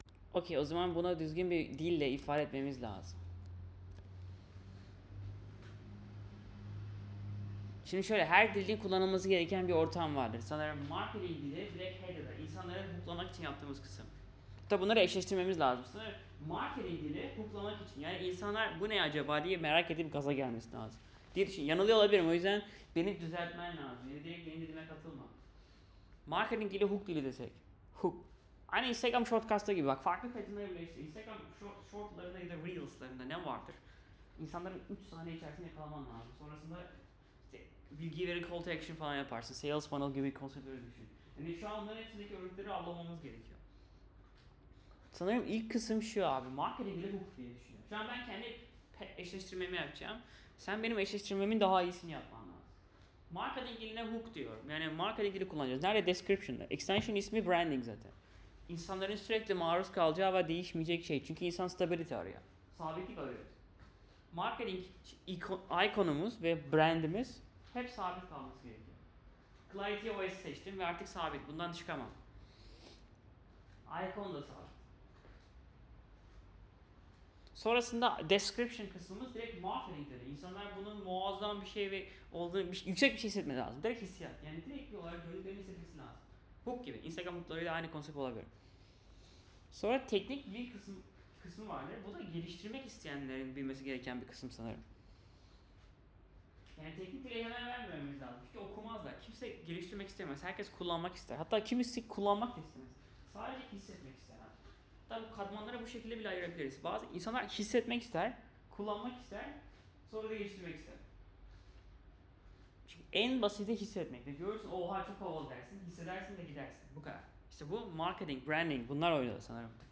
claude code'la konusurken, yuruyerek konusmusum dalgali bir ses var :D (türkçe)